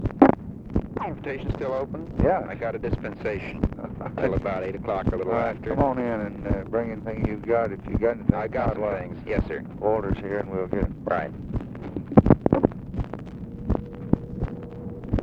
Conversation with MCGEORGE BUNDY, February 25, 1964
Secret White House Tapes